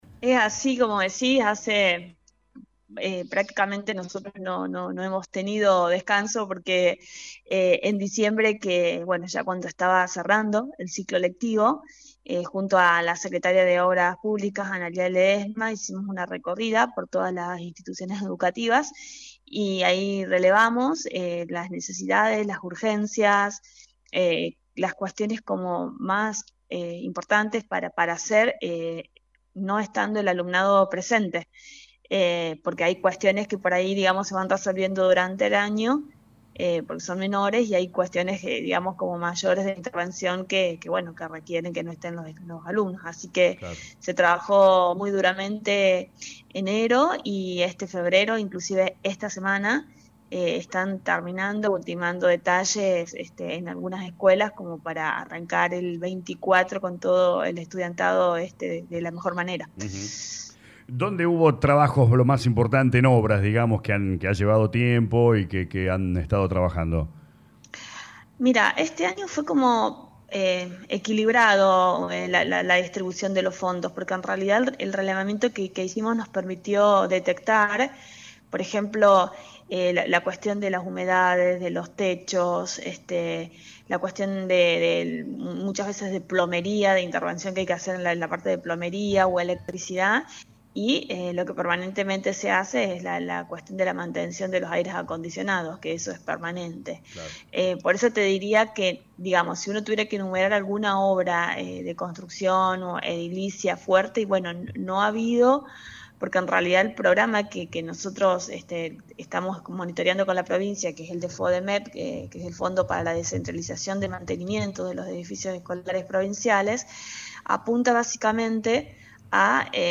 Lo aseguró la Secretaria de Educación y Cultura Lic. Marisol Nuñez en dialogo con LA RADIO 102.9 donde repasó las tareaas de obras y mantenimiento en los distintos colegios urbanos y rurales de laa ciudad.